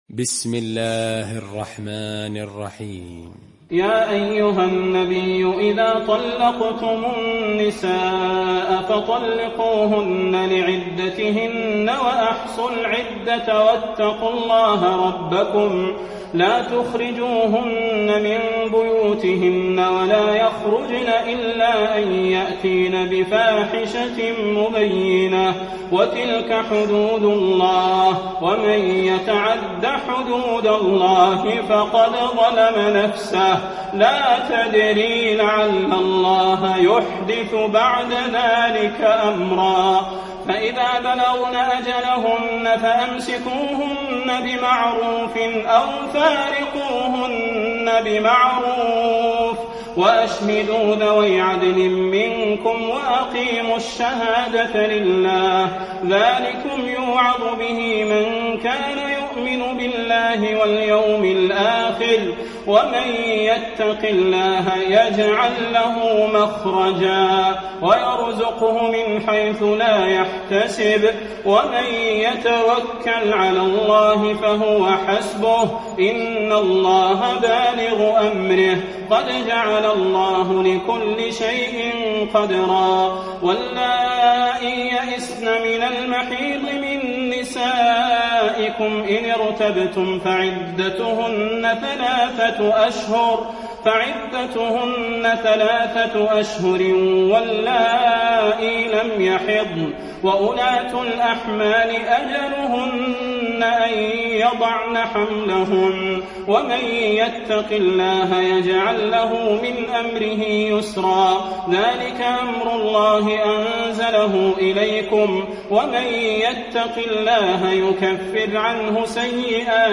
المكان: المسجد النبوي الطلاق